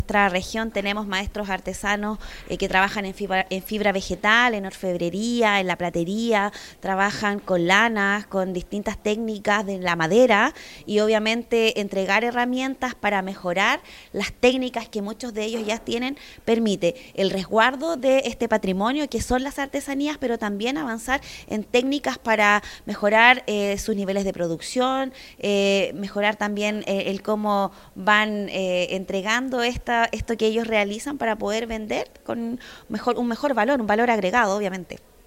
La Seremi de las Culturas, las Artes y el Patrimonio, Cristina Añasco, estuvo presente en la ceremonia y destacó la importancia de abrir estos espacios para la promoción y preservación de las artesanías ancestrales.